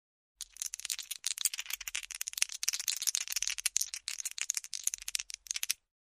Звуки игральных костей
На этой странице собраны разнообразные звуки игральных костей: от легкого потрясывания в руке до звонкого удара о стол.